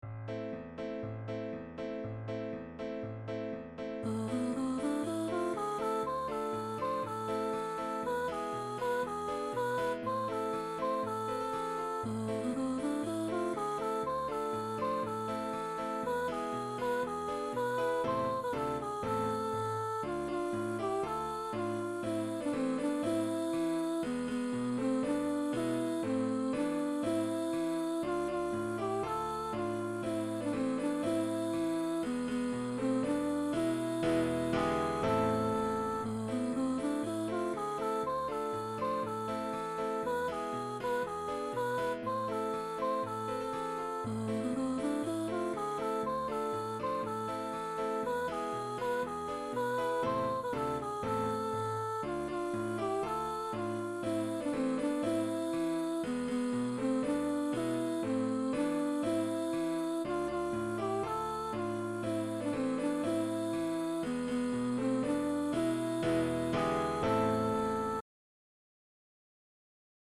Unison / piano